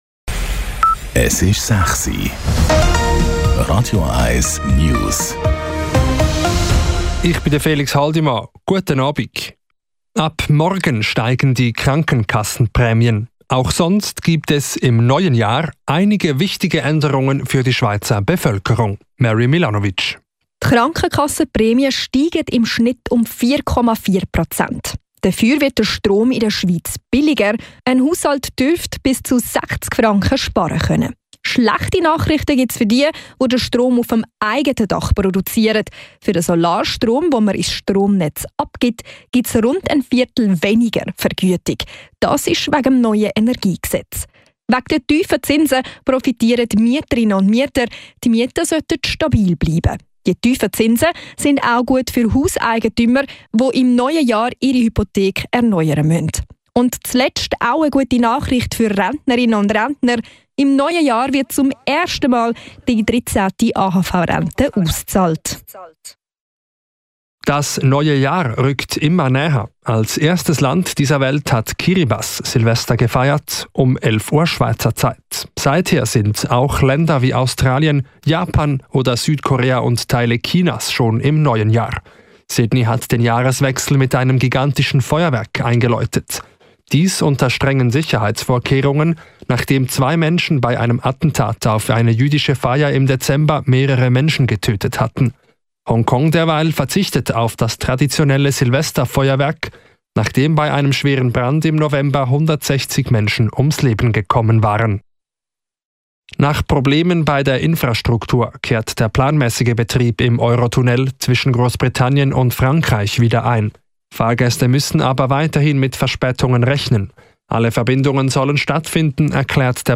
Die letzten News von Radio 1